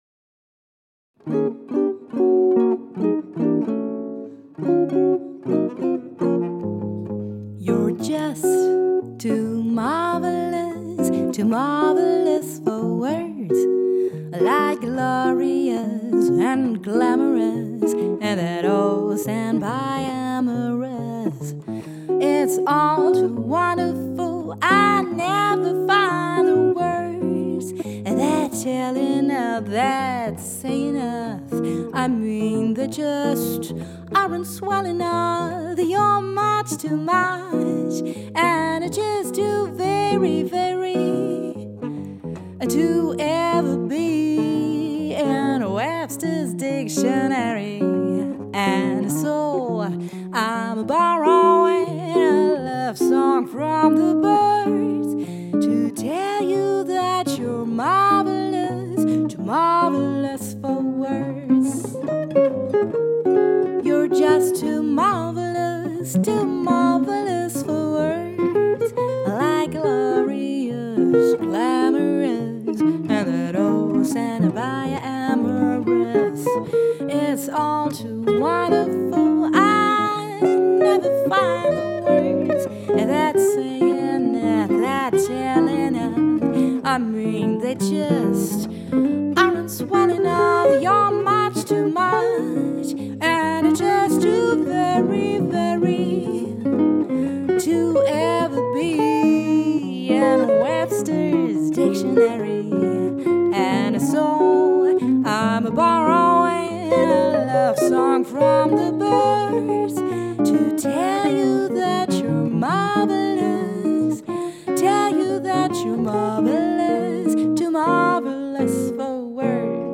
Jazzstandards